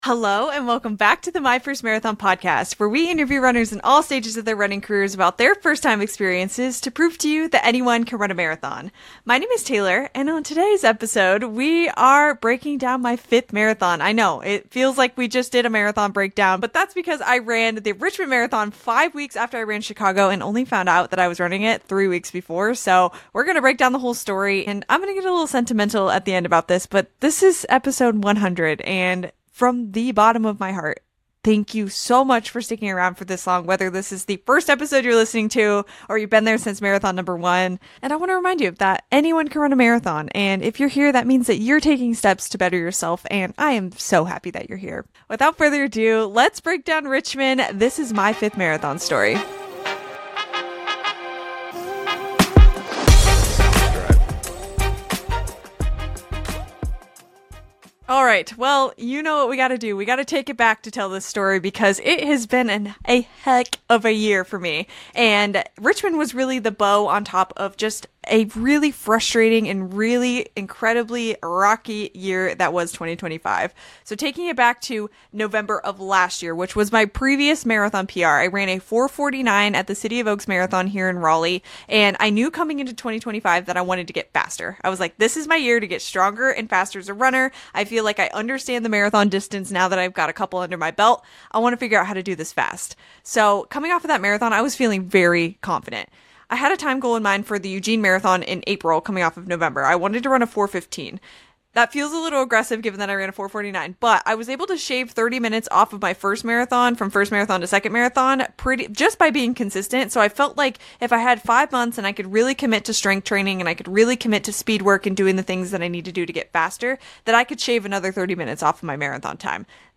MY 5th Marathon (Solo Episode - HAPPY 100 EPISODES!!)